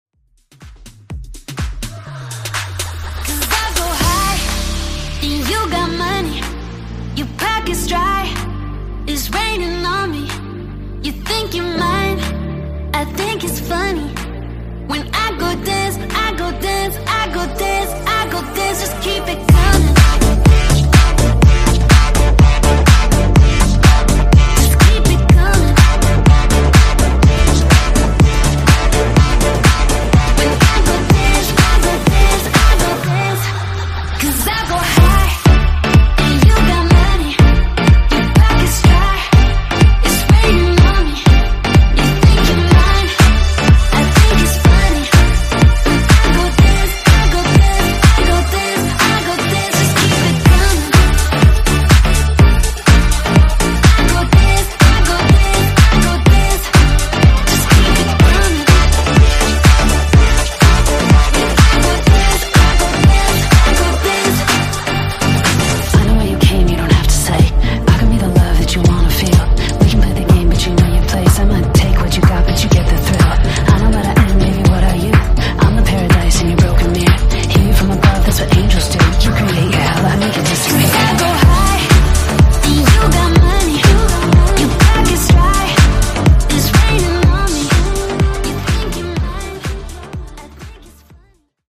Genres: DANCE , EDM , RE-DRUM
Clean BPM: 124 Time